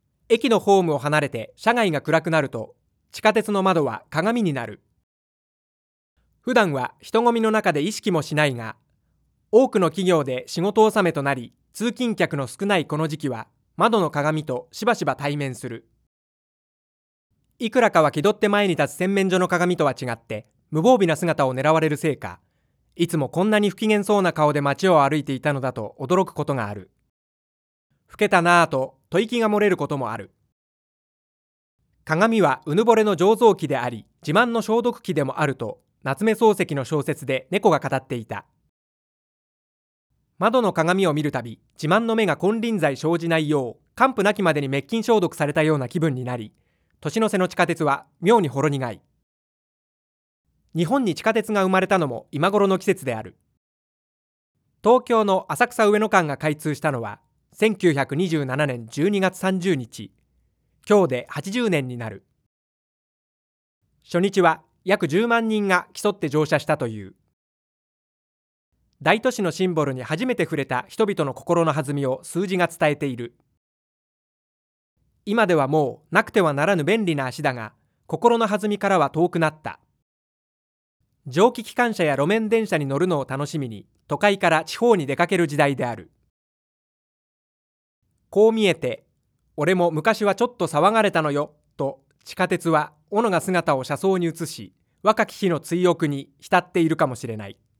話速バリエーション型音声データベース
このページは，SRV-DB のダウンロードページです．表内の各ファイルは，Microsoft WAVE形式 (.wav ファイル) にて提供しております．また，一括ダウンロード用のファイルは，ZIP形式にて圧縮してあります．音声データは，PCM 44,100 Hz 16bit で収録しております．チャネル数は，基本的に モノラル(1ch) ですが，一部のものはステレオとなっています．
3. 発話のプロフェッショナルによる編集手帳（読売新聞）の読み上げ
11.31 ［モーラ／秒］ ダウンロード ダウンロード
絨毯敷，カーテン有．